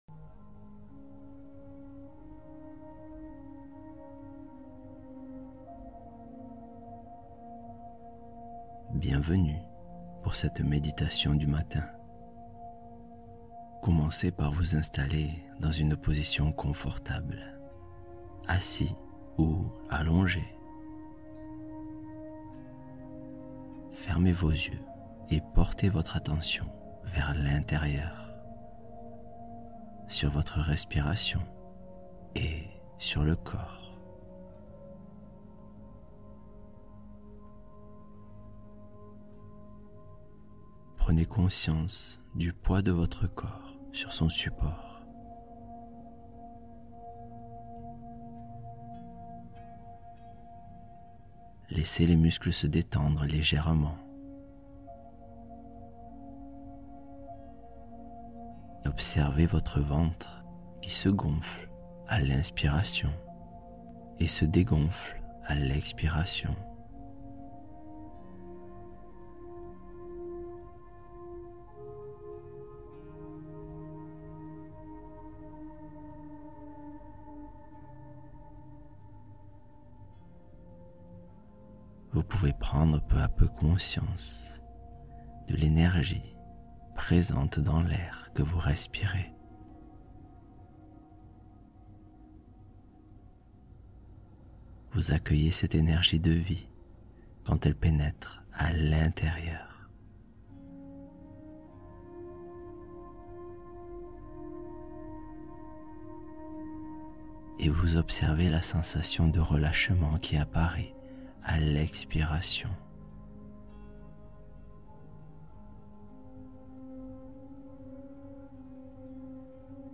Matin Énergie : Méditation de positivité pour bien démarrer la journée